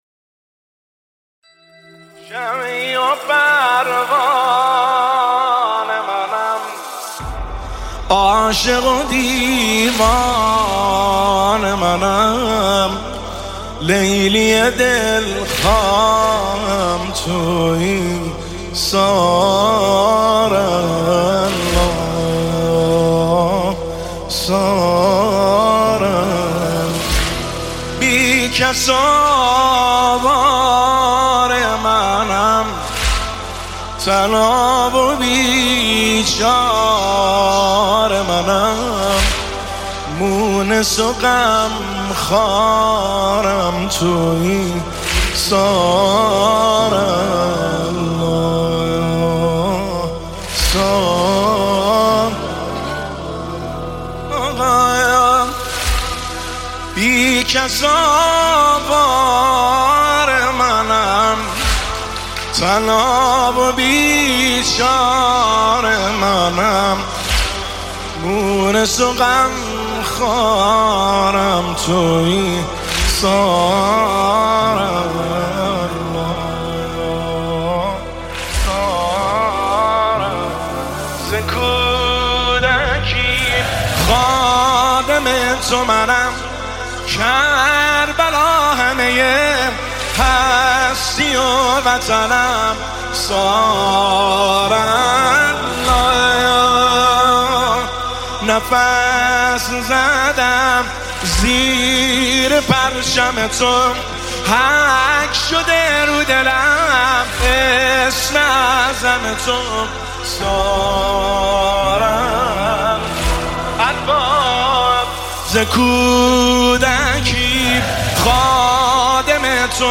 مناجات با امام حسین (ع)
نماهنگ